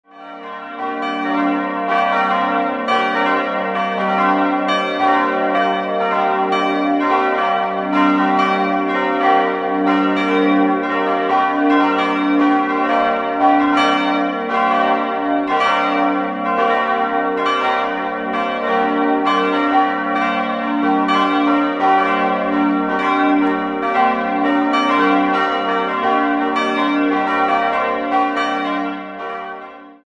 Leicht erhöht in der Mitte des rund 200 Einwohner zählenden Dorfes steht die Pfarrkirche St. Martin mit ihrem Zwiebelturm. Seit 1996 rufen vier neue Glocken zum Gottesdienst. 4-stimmiges ausgefülltes G-Moll-Geläute: g'-b'-c''-d'' Die Glocken wurden 1996 in der Gießerei Rudolf Perner in Passau gegossen.